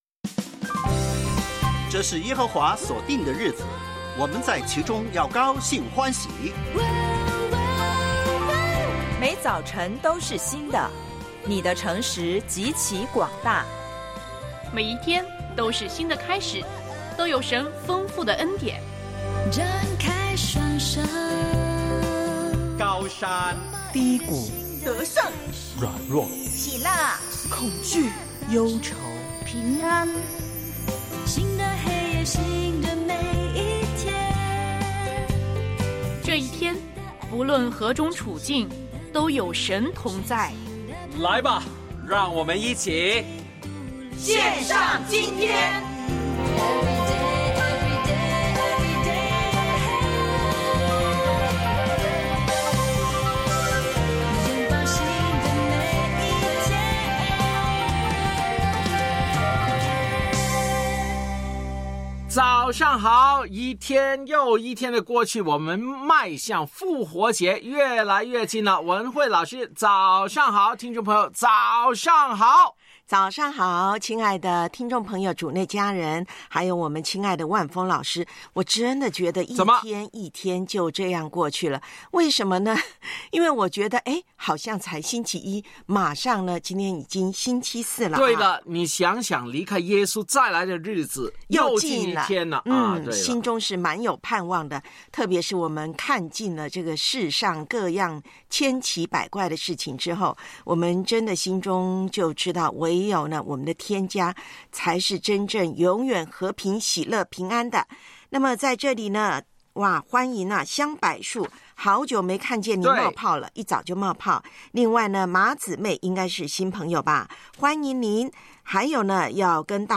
教会年历灵修：诗篇32:1-5、8；音符里的圣经（4）吗哪；男高音独唱曲《天赐神粮》；我爱背金句：士师记13:5